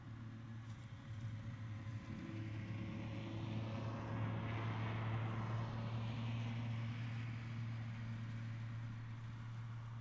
Zero Emission Subjective Noise Event Audio File (WAV)
Zero Emission Snowmobile Description Form (PDF)